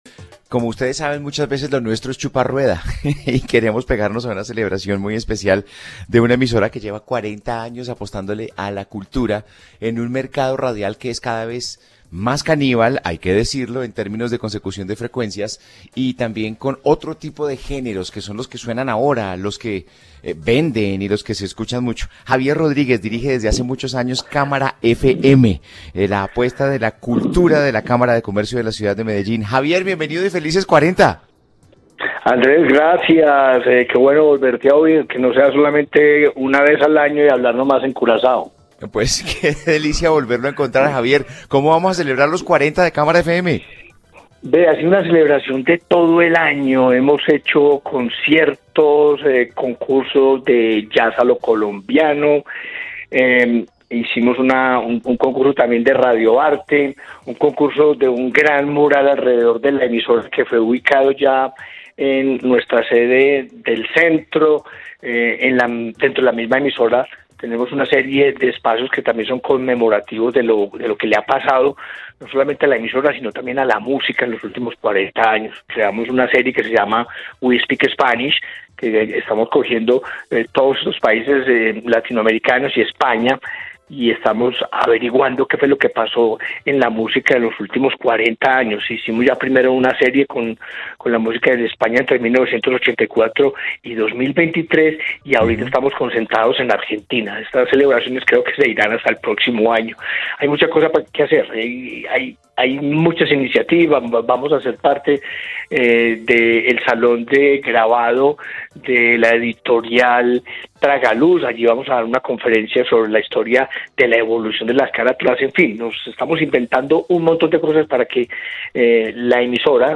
habló para los micrófonos de ´A Vivir Que Son Dos Días´ y menciona que para la celebración se han realizado conciertos con “Jazz a lo colombiano”